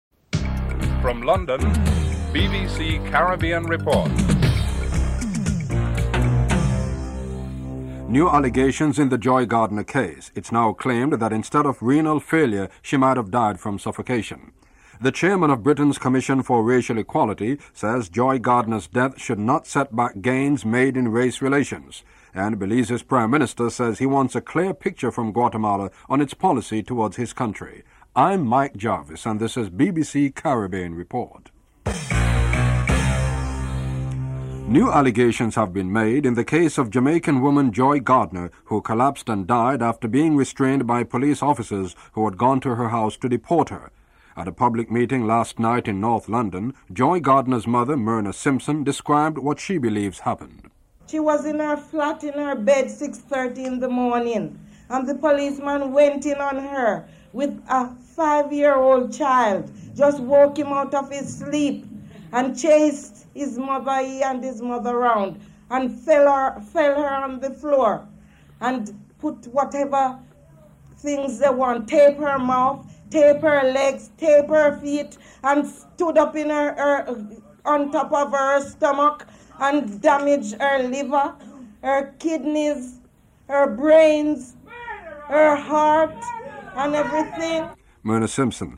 1. Headlines (00:00-00:38)
Interview with Manuel Esquivel, Prime Minister of Belize (08:45-12:48)